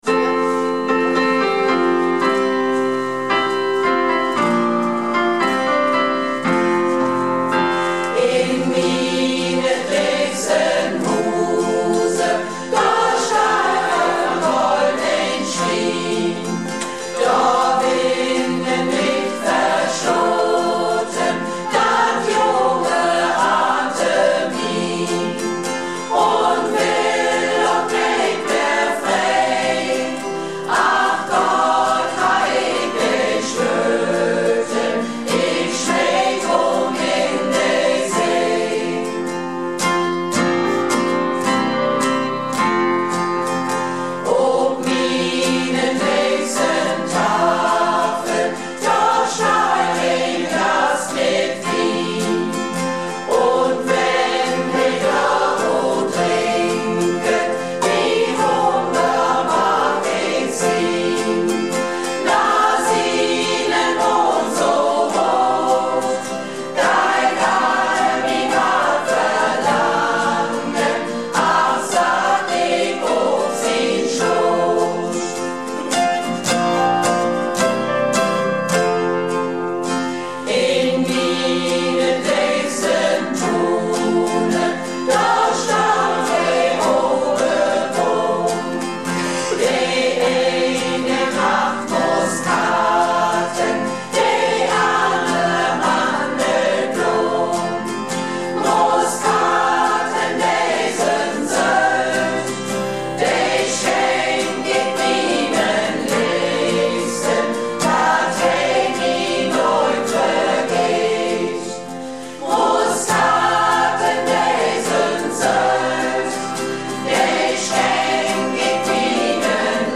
Malle Diven - Probe am 19.09.17